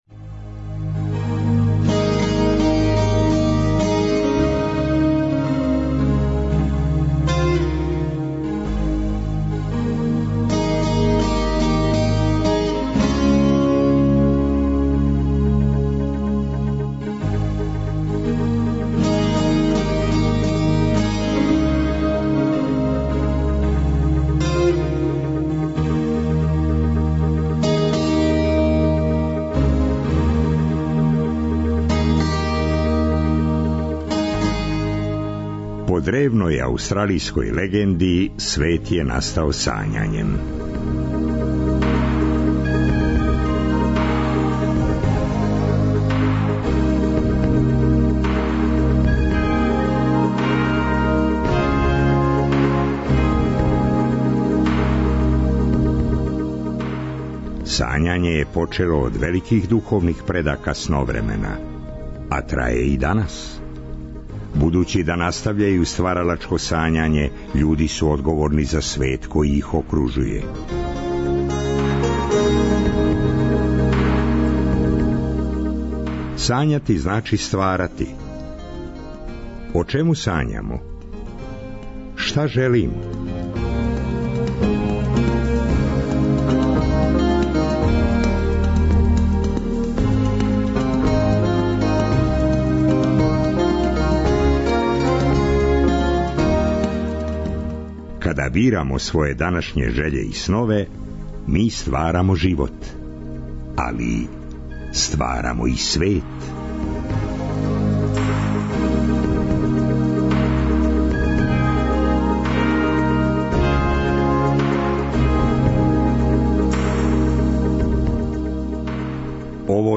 У трећем и четвртом сату емисије - музика, поезија, приче...